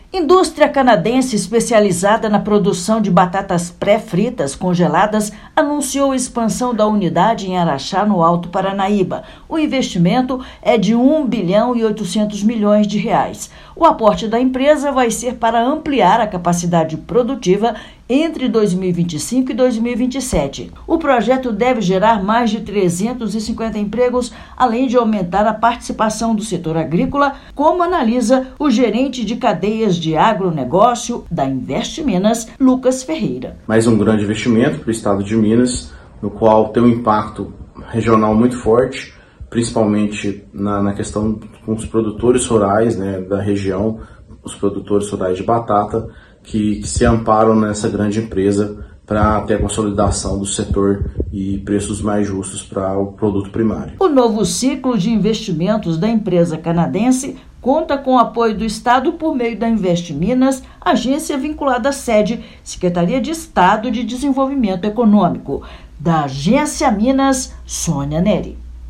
McCain, líder global na produção de batatas pré-fritas congeladas, vai se expandir com maior aporte da história da empresa na região. Ouça matéria de rádio.